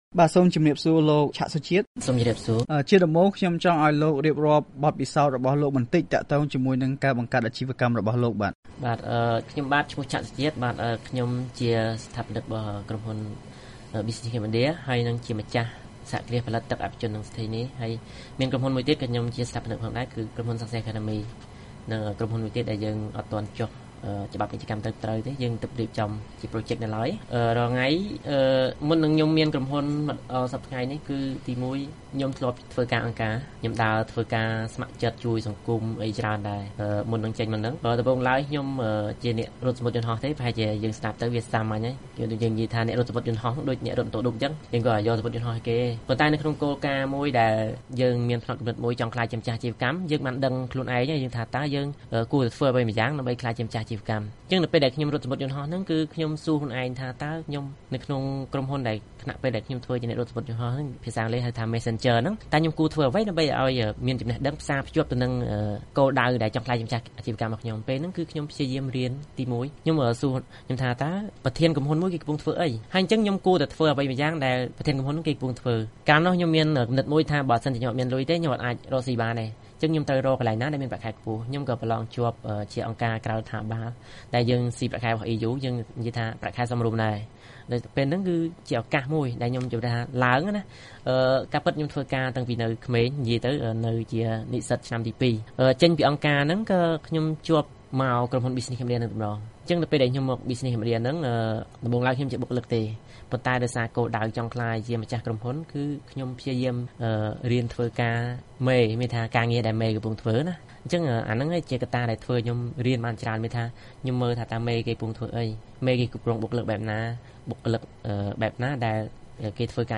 បទសម្ភាសន៍ VOA៖ សហគ្រិនវ័យក្មេង៖ ចង់ក្លាយជាម្ចាស់អាជីវកម្ម ត្រូវតែប្រើខ្លួនឯងឲ្យអស់សមត្ថភាព